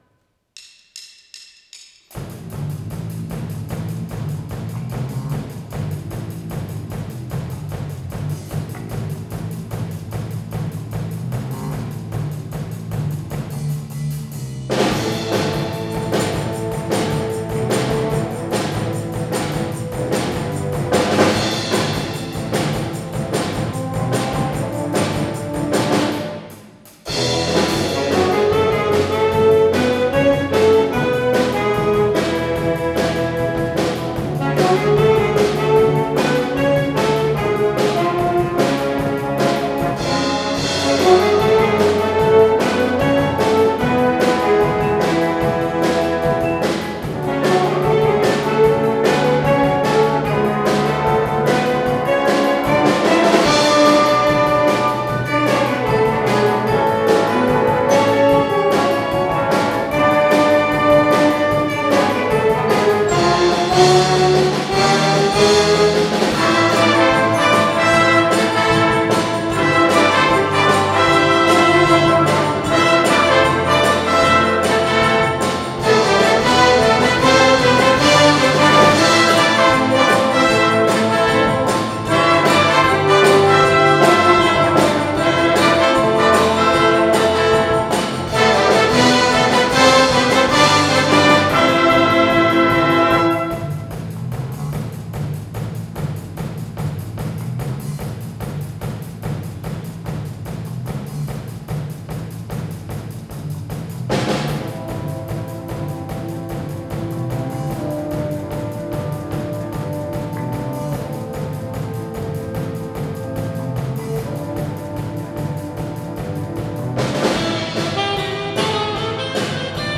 オリジナル曲 録音会 3月　鈴鹿市民会館
当然、貸切ですので、広々と準備・音出しです。 そして、録音会の始まりです。